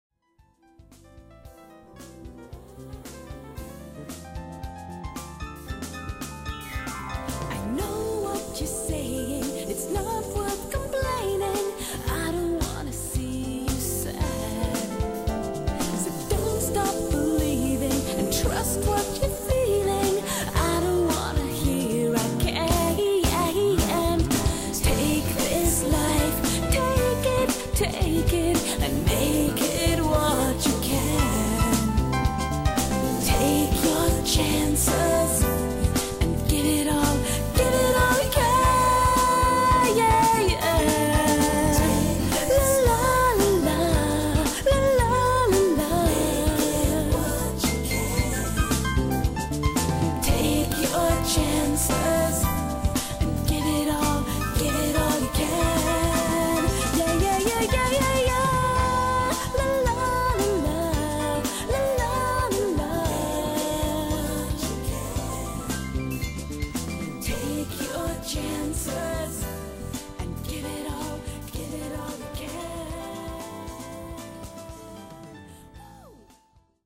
∼本專輯非常有巧技地融入了當代爵士樂、節奏藍調、並帶有歐洲與拉丁的風味。